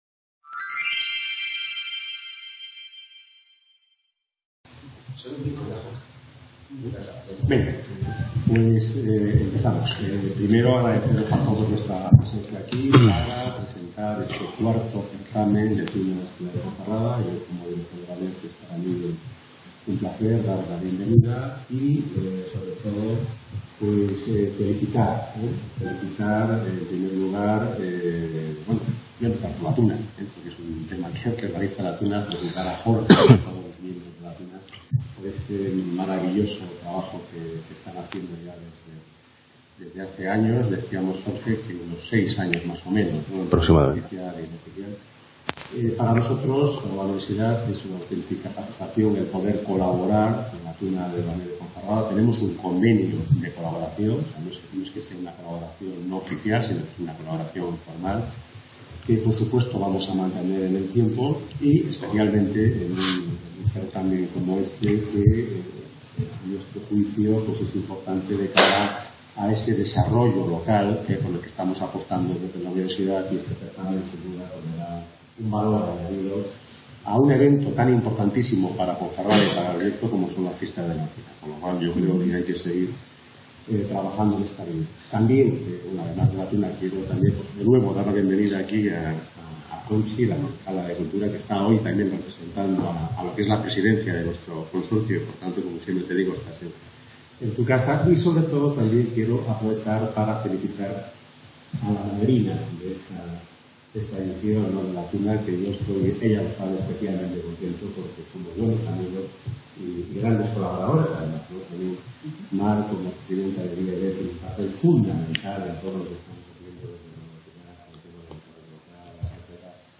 El Certamen de Tunas, se desarrollará en distintos puntos de la ciudad, pero ofrece en el Bergidum el acto central, donde se podrá constatar la calidad musical y escénica de las agrupaciones participantes.